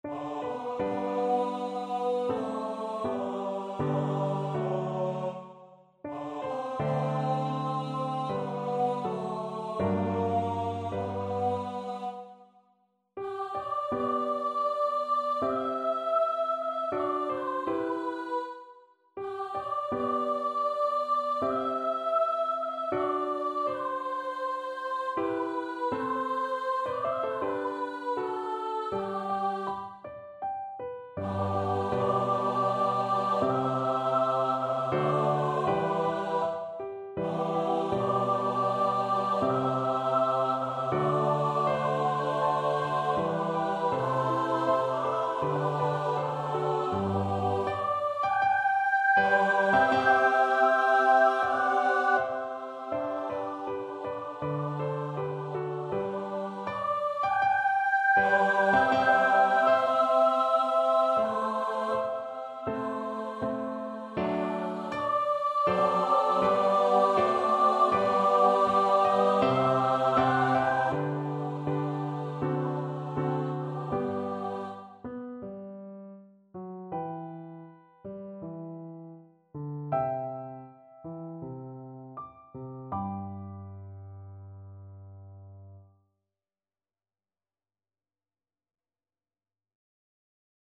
Free Sheet music for Choir (SATB)
Andante
4/4 (View more 4/4 Music)
Classical (View more Classical Choir Music)